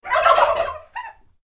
جلوه های صوتی
دانلود صدای بوقلمون 2 از ساعد نیوز با لینک مستقیم و کیفیت بالا